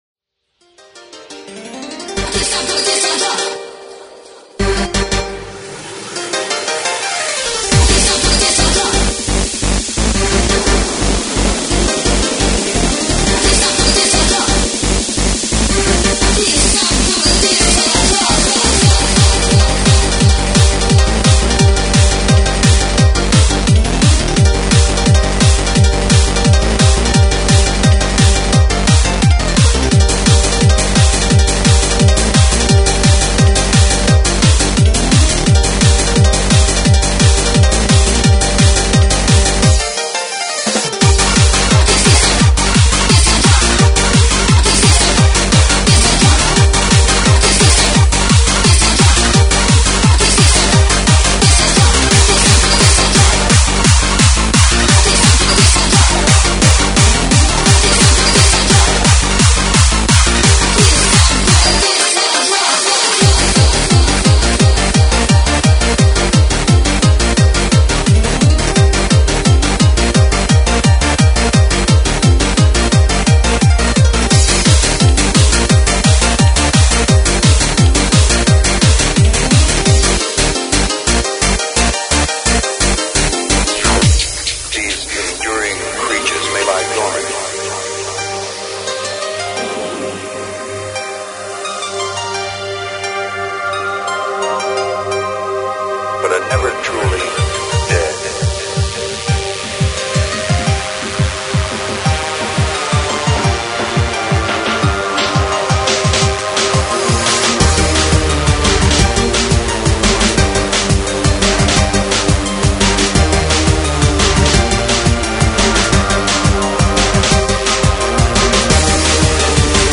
Freeform